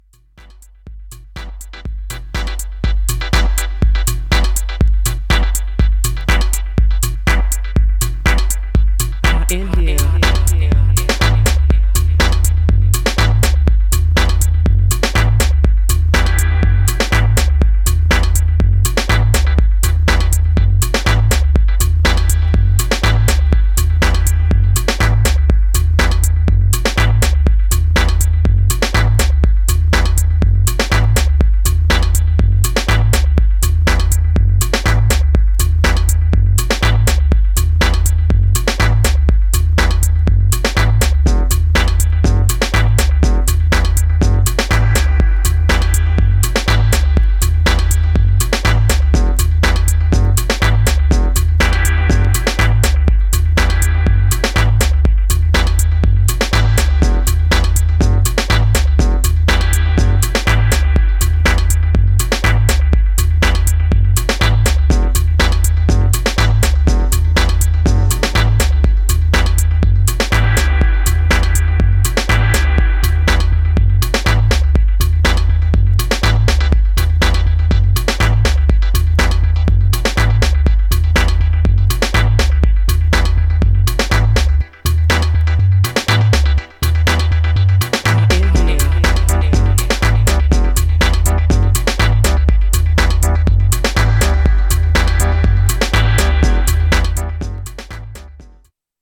Styl: House, Breaks/Breakbeat, Minimal